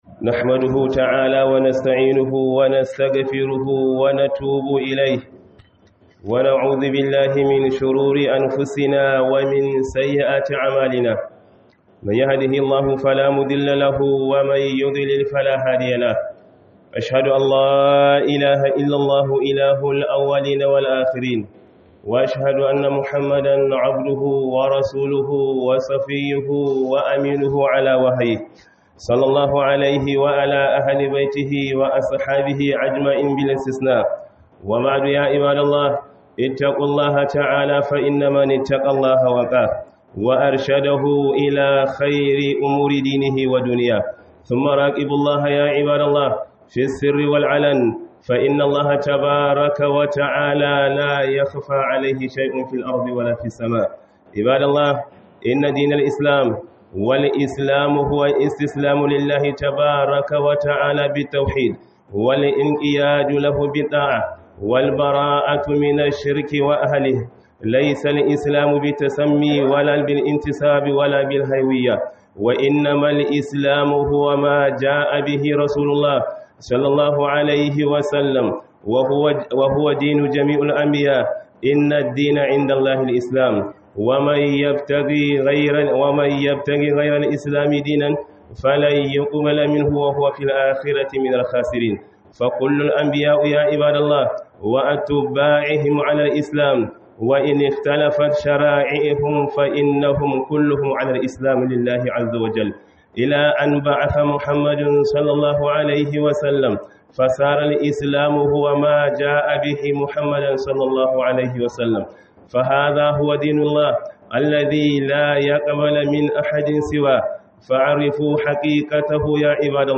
Khuduba